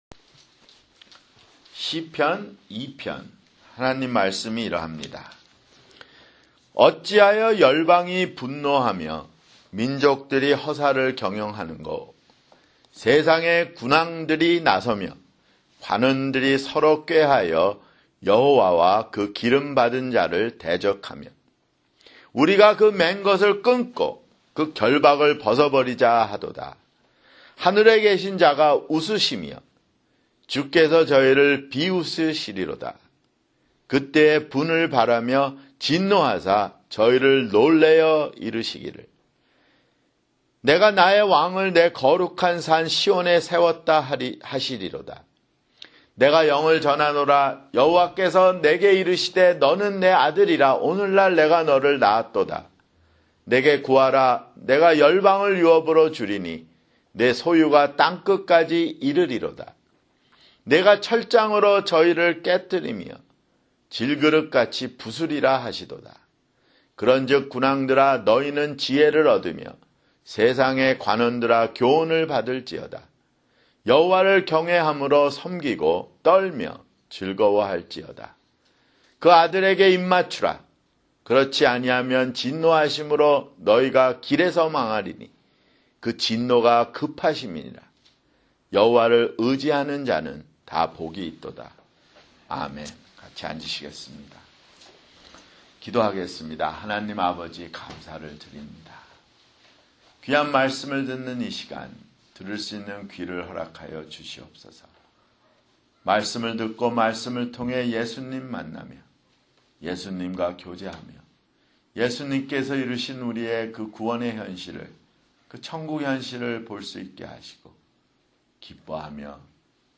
[주일설교] 시편 (2)